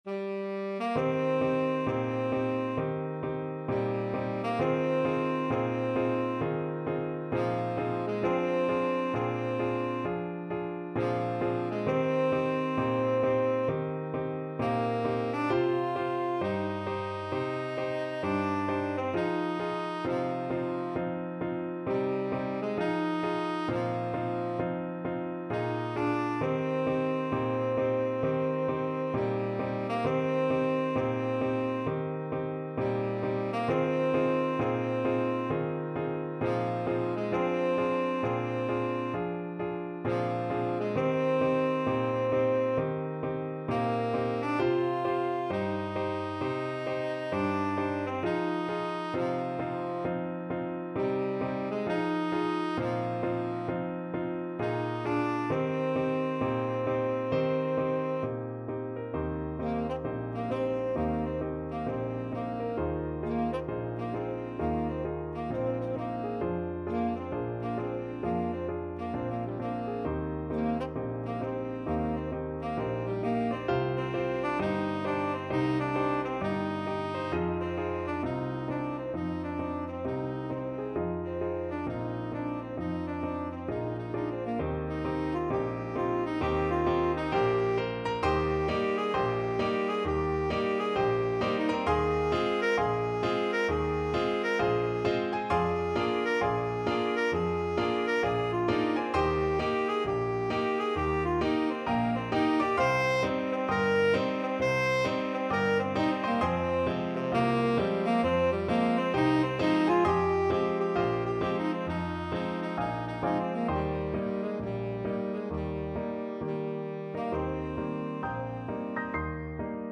2/2 (View more 2/2 Music)
Blues Tempo (=66)
Jazz (View more Jazz Tenor Saxophone Music)